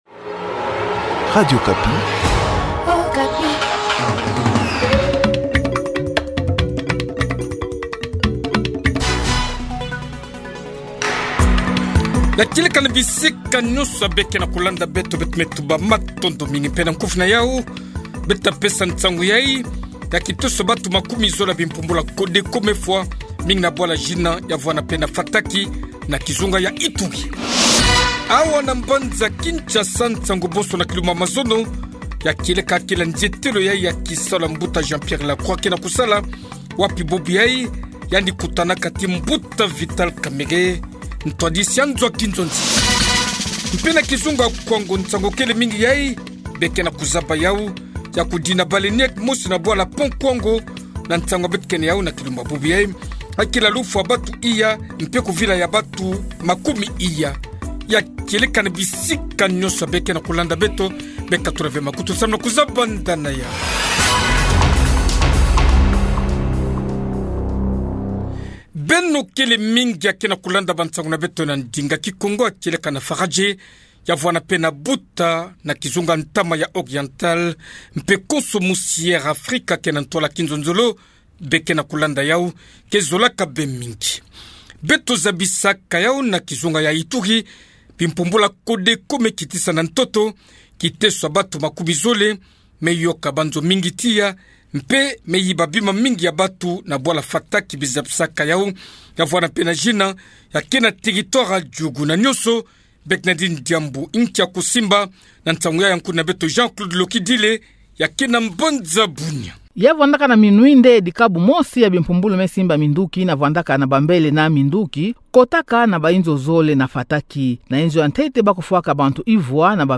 Journal Soir
Edition de ce soir 18h30'' Na nkufi ya bansangu yayi, beto ta pesa nsangu ya kiteso ya bantu makumi zole ya bimpumbulu ya Codeco me fwa, mingi na bwala Jina, mpe na Fataki na kizunga ya Ituri.